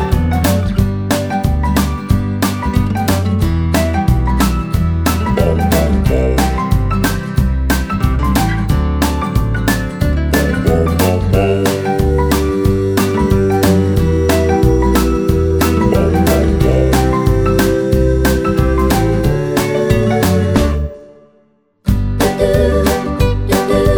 no Backing Vocals Country (Male) 2:35 Buy £1.50